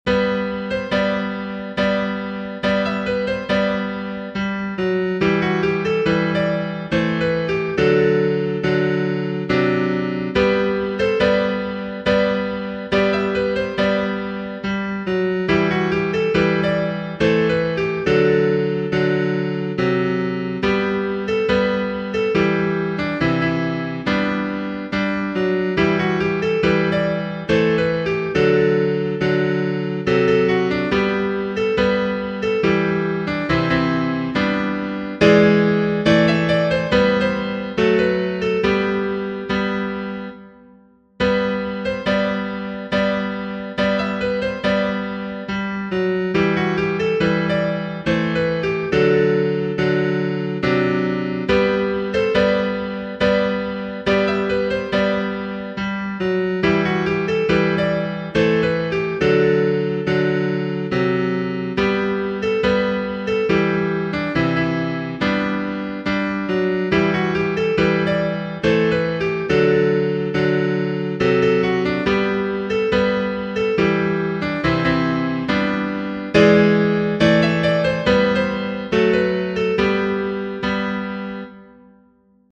Tradizionale Genere: Folk Testo di anonimo Mândra mea de la Ciugur, Multe vorbe-n sat se-aud, Spune lumea pe la noi Că fac seara drum la voi, măi.